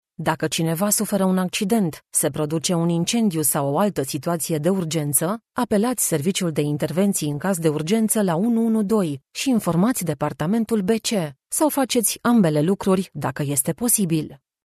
Get the distinctive native Romanian voice artist for your project that stays on people's minds and has them telling others about it.
Sprechprobe: eLearning (Muttersprache):
PITCH: mid-range, female, 30-50 yrs. TONE: dynamic, relatable, pleasant ACCENT: neutral Romanian, English with an Eastern European Accent My custom-built home studio is fully connected for directed sessions, I record with a NEUMANN TLM 107 Microphone and Scarlet2i2 generation 4 interface.